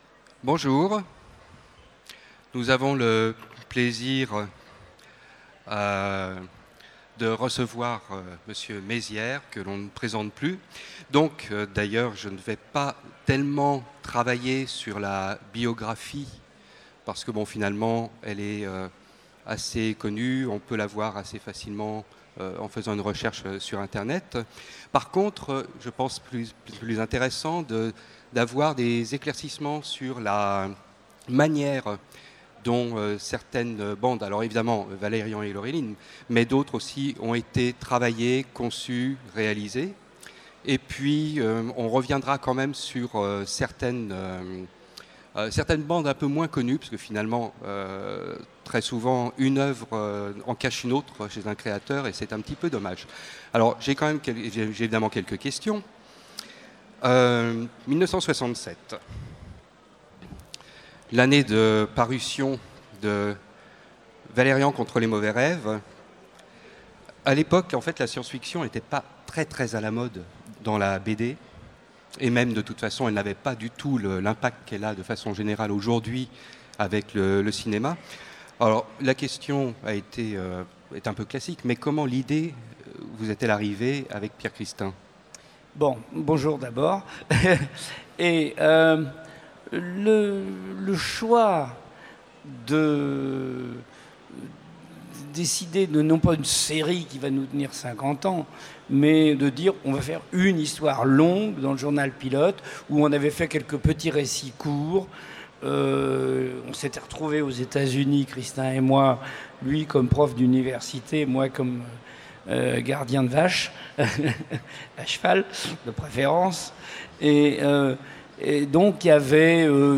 Utopiales 2017 : Rencontre avec Jean-Claude Mézières
Rencontre avec un auteur Conférence